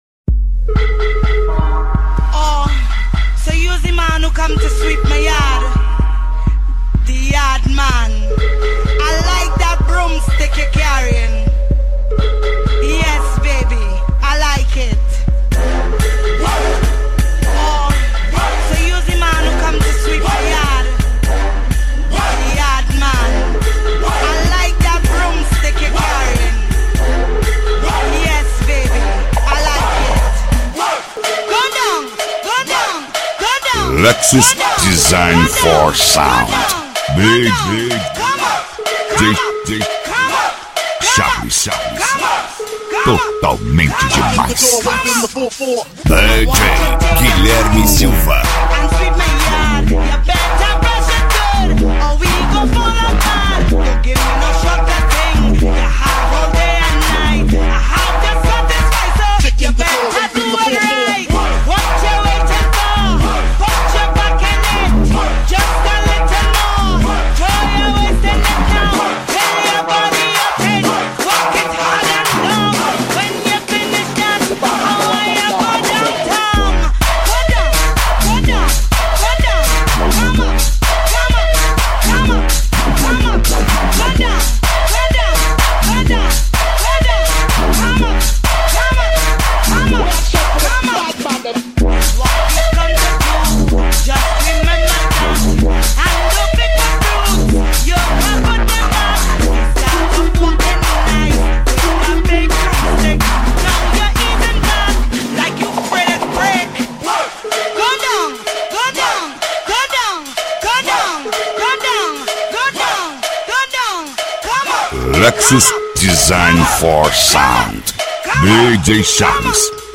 DEEP HOUSE.